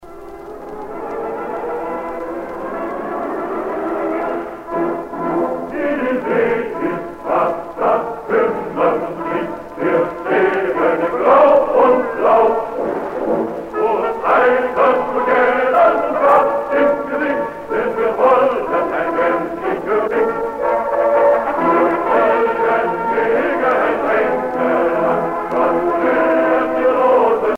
gestuel : à marcher
circonstance : militaire
Pièce musicale éditée